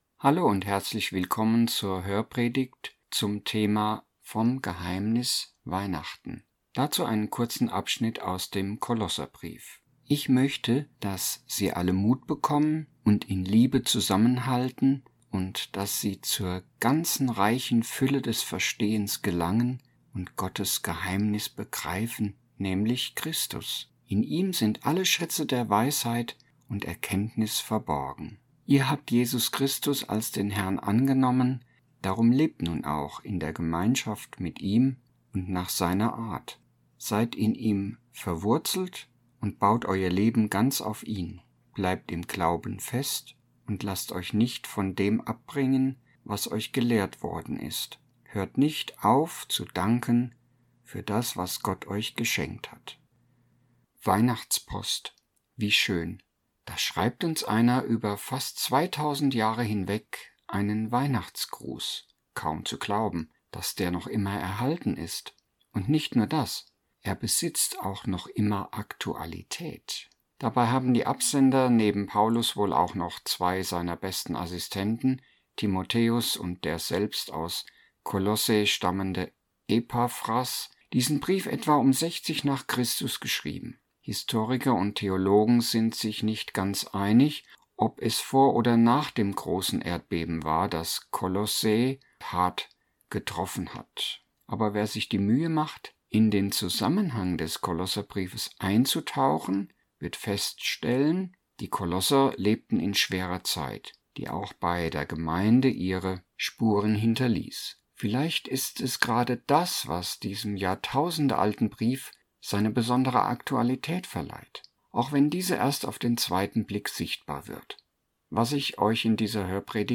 Hörpredigt-ZV-Weihnachten_2024.mp3